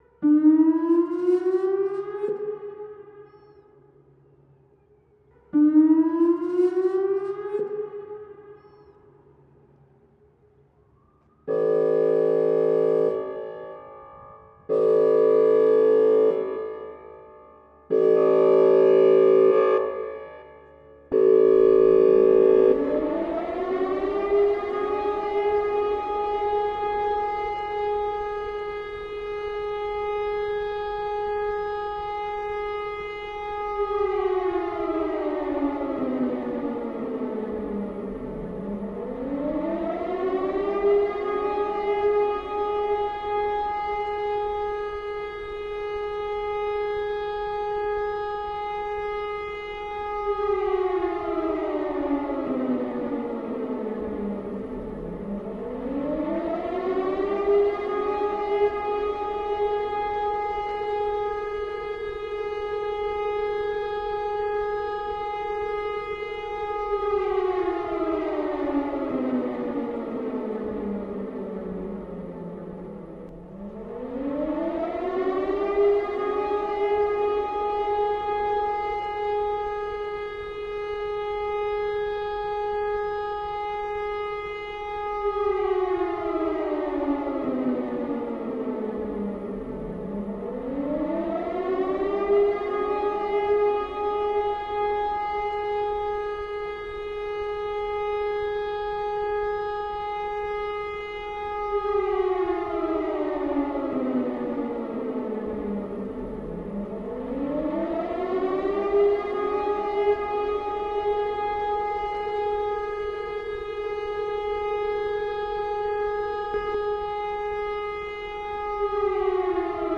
blowout_siren_agr.ogg